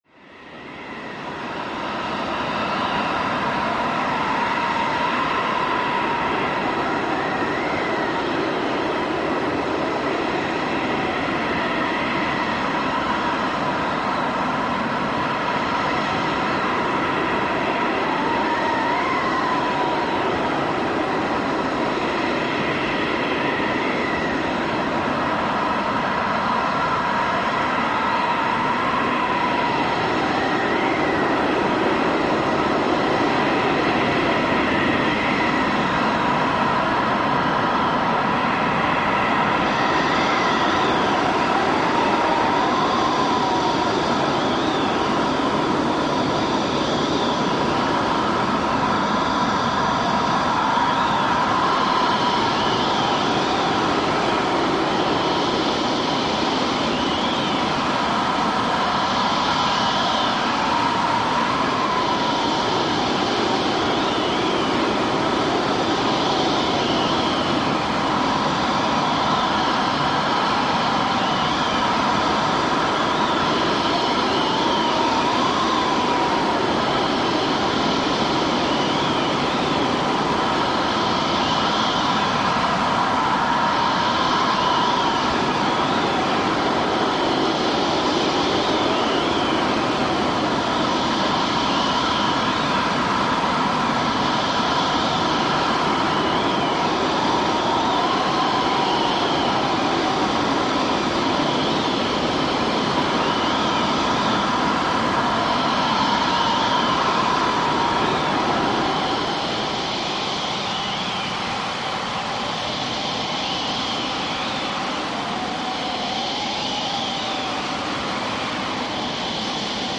HurricaneWindHowl AHS027901
Hurricane Wind, Howling Tone, Very High Octave, Stereo Panning